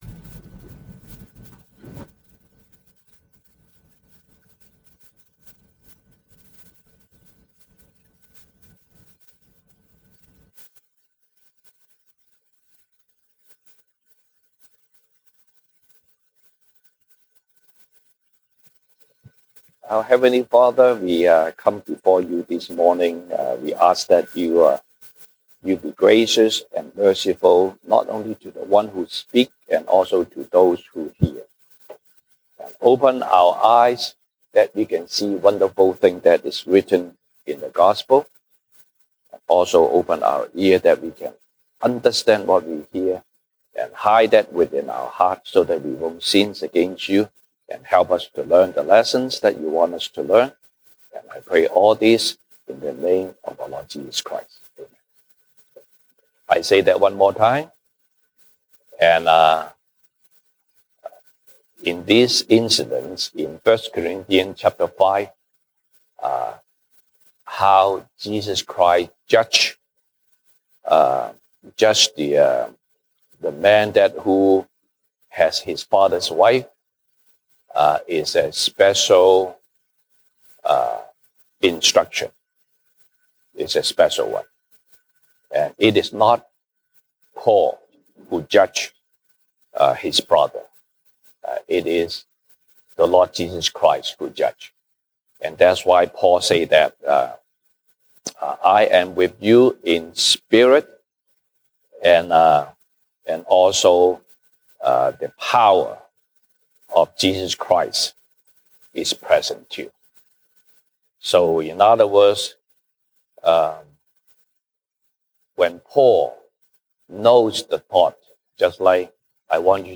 西堂證道 (英語) Sunday Service English: This is how God saves sinner